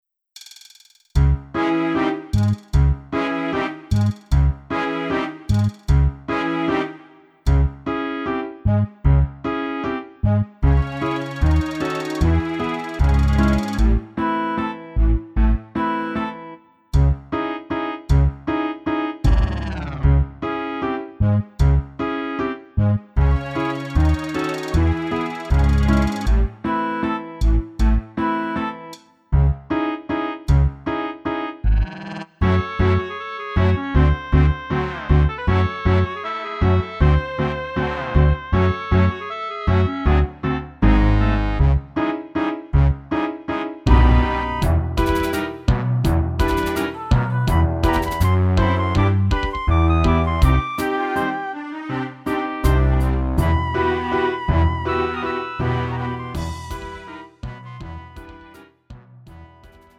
음정 -1키 2:45
장르 가요 구분 Lite MR
Lite MR은 저렴한 가격에 간단한 연습이나 취미용으로 활용할 수 있는 가벼운 반주입니다.